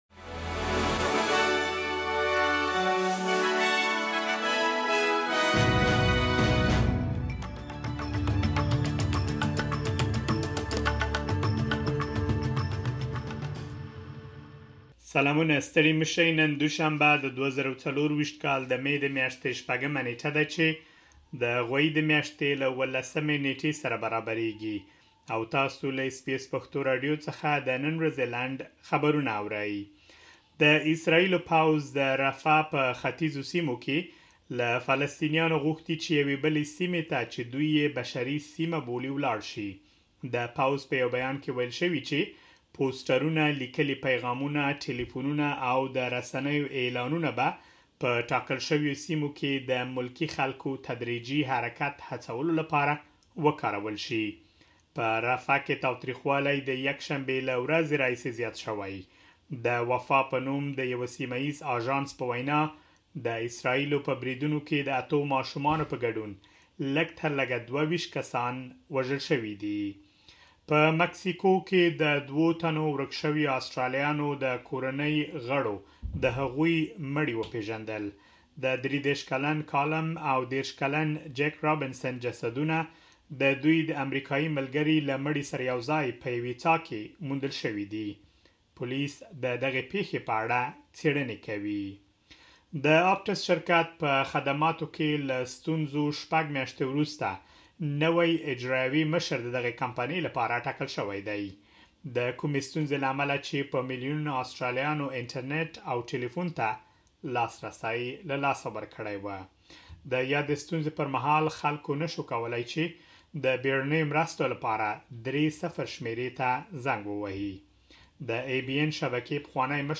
د اس بي اس پښتو راډیو د نن ورځې لنډ خبرونه|۶ مې ۲۰۲۴